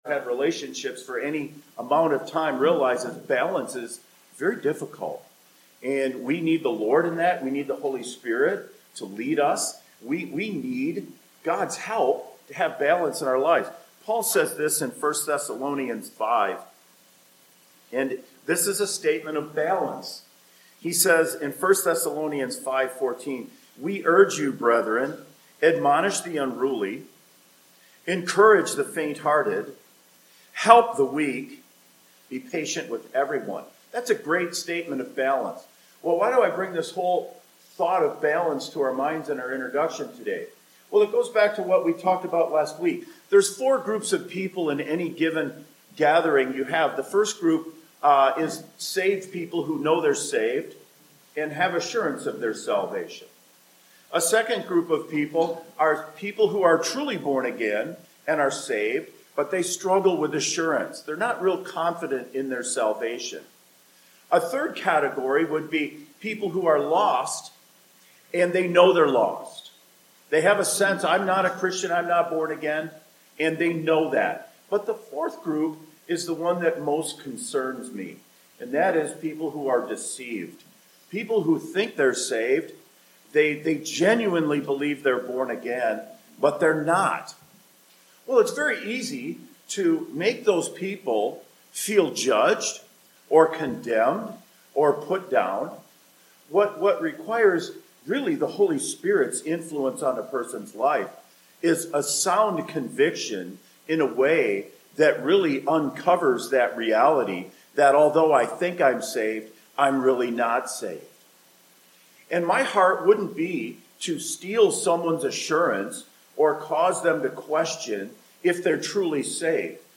Various Service Type: Morning Worship Topics